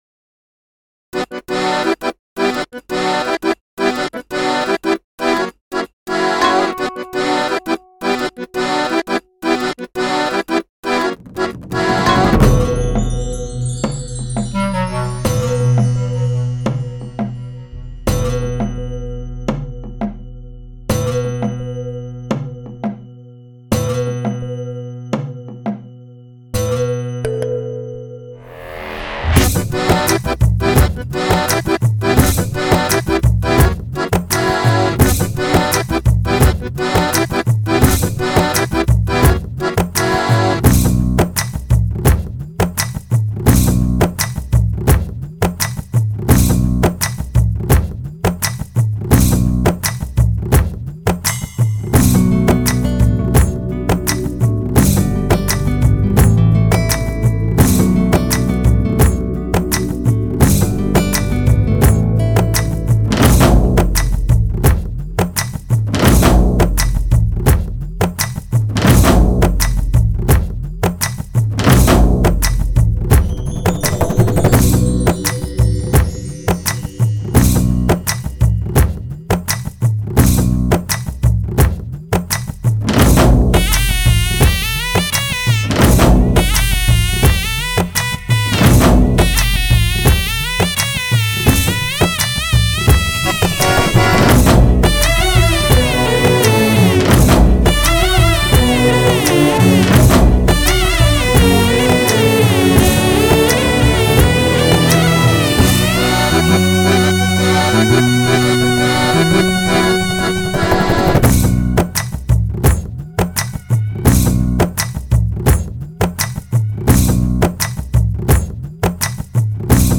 Singer: Karaoke Version